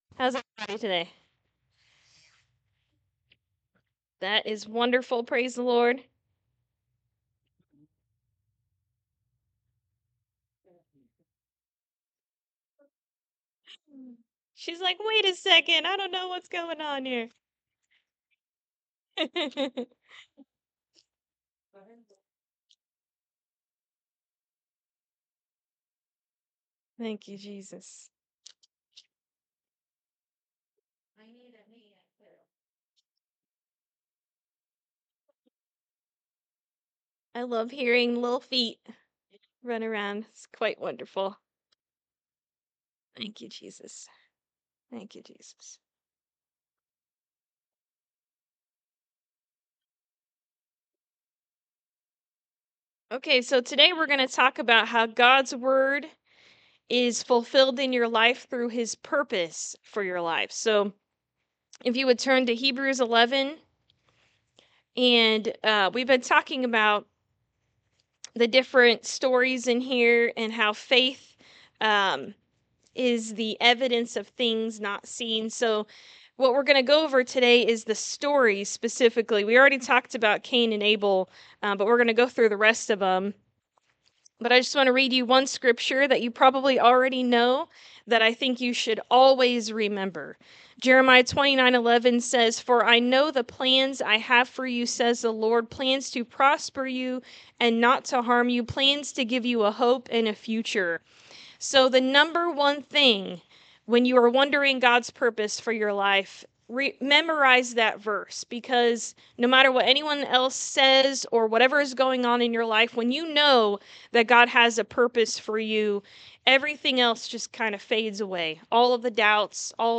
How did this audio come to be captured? Jeremiah 29:11 Service Type: Sunday Morning Service Are you wondering what God’s purpose is for your life?